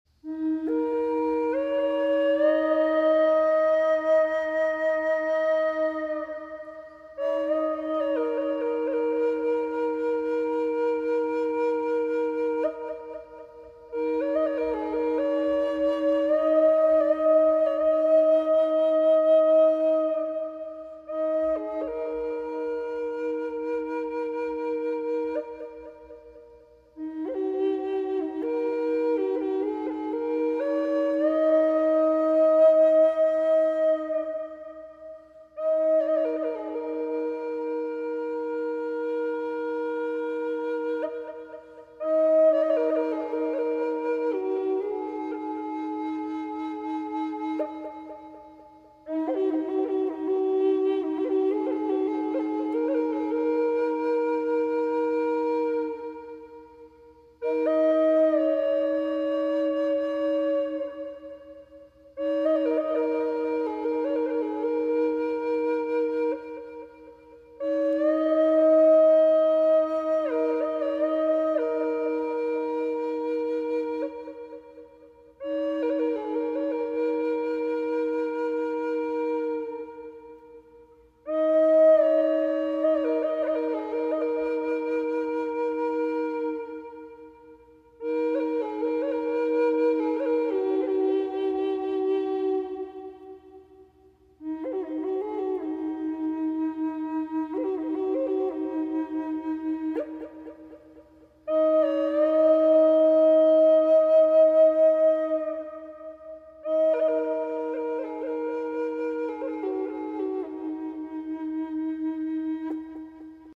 Evil eyes removal reiki ASMR sound effects free download